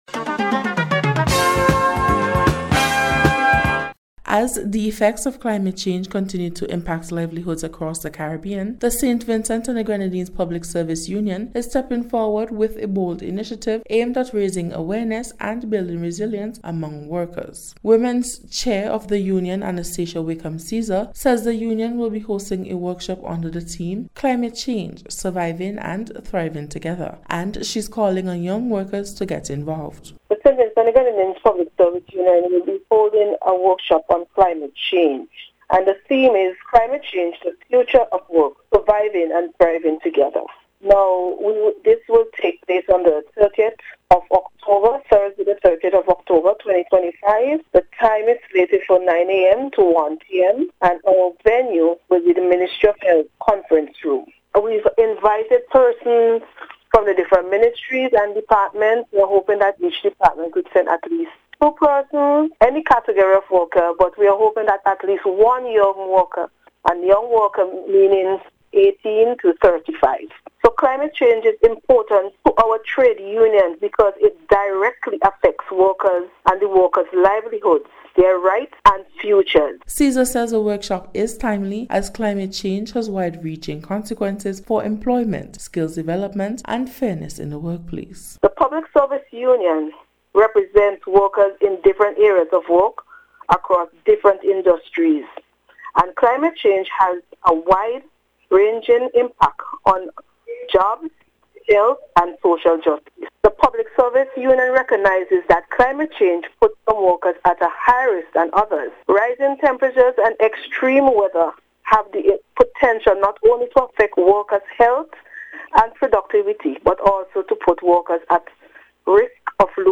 NBC’s Special Report – Thursday September 25th 2025
PSU-CLIMATE-WORKSHOP-REPORT.mp3